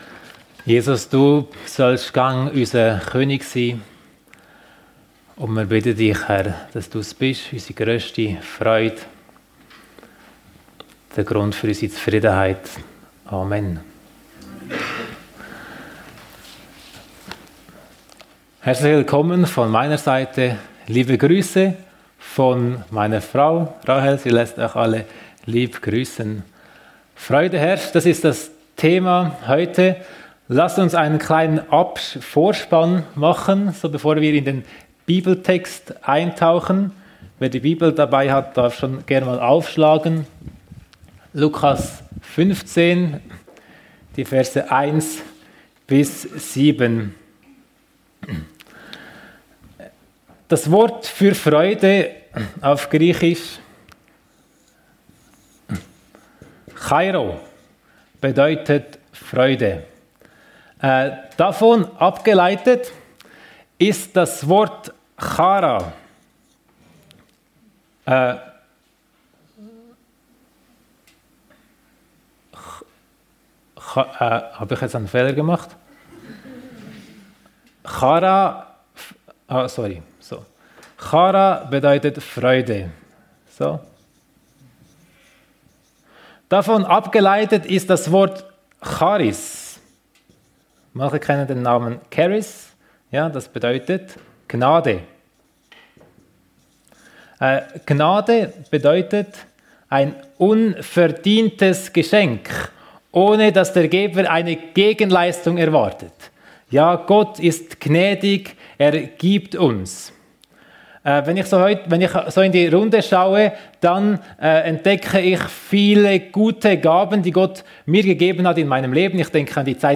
Freude herrscht ~ FEG Sumiswald - Predigten Podcast